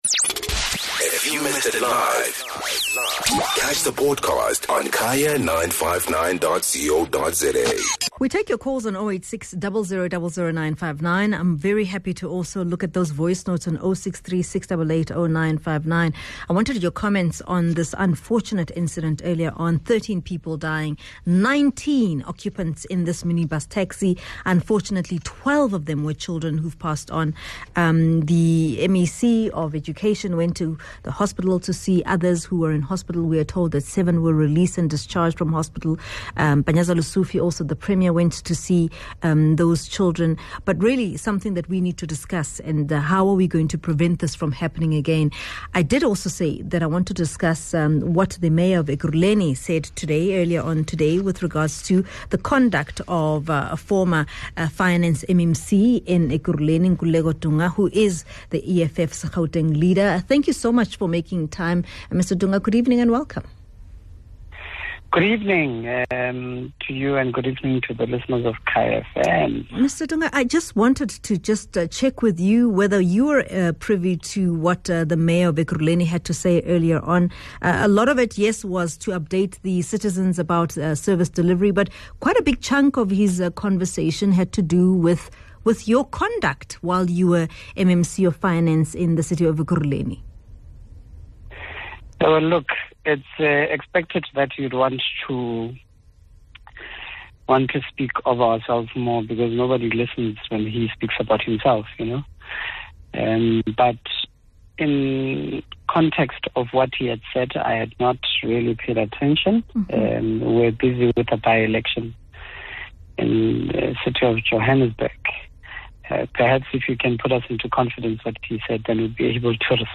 Guest: Nkululeko Dunga- EFF Gauteng Leader